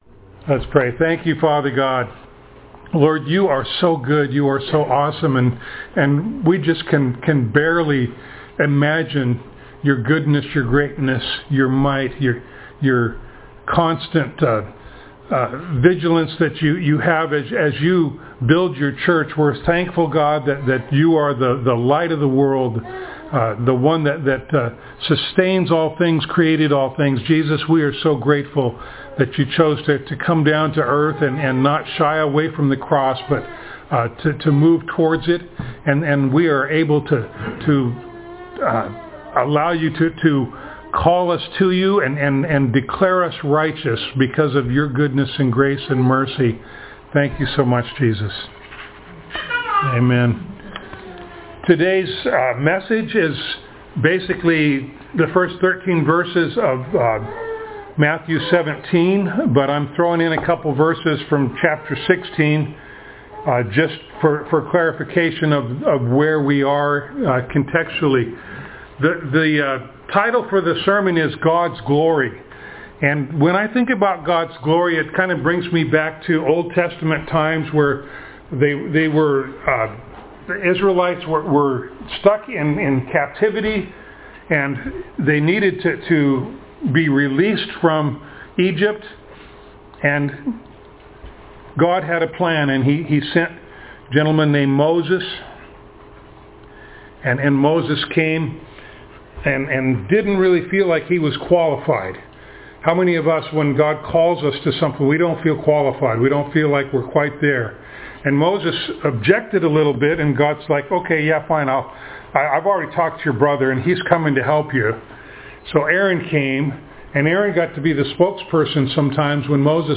Matthew Passage: Matthew 16:27-17:13, Mark 9:1-13, Luke 9:27-36, John 1:14, Revelation 21:23-27, 2 Peter 1:16-18, Acts 9:1-9 Service Type: Sunday Morning